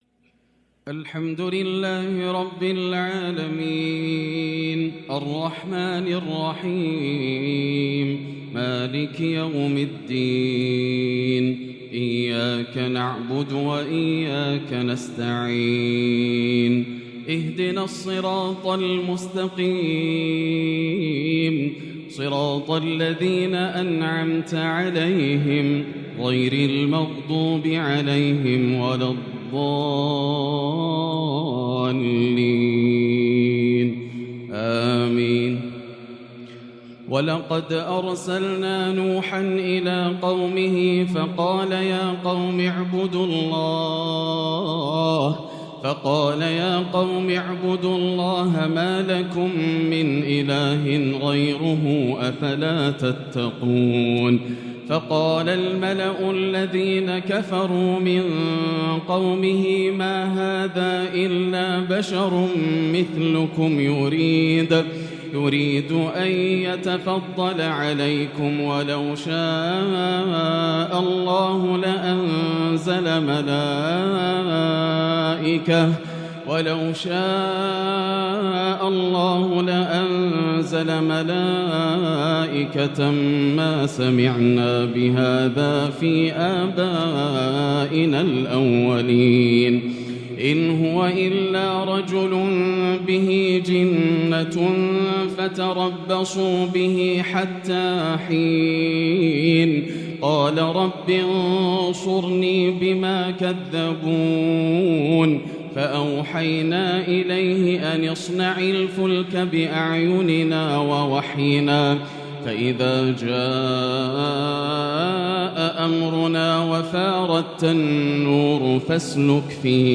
ترتيل خاشع ومميز لخواتيم سورة المؤمنون - ليلة 22 رمضان 1443هـ > الليالي الكاملة > رمضان 1443هـ > التراويح - تلاوات ياسر الدوسري